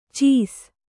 ♪ cīs